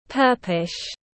Màu tím phớt tiếng anh gọi là purplish, phiên âm tiếng anh đọc là /ˈpɜː.pəl.ɪʃ/.
Purplish /ˈpɜː.pəl.ɪʃ/